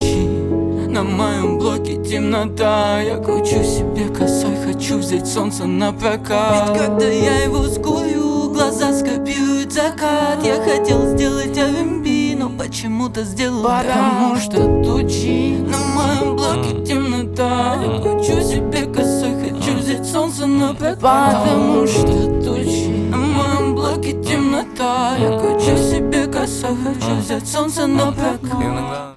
рэп
грустные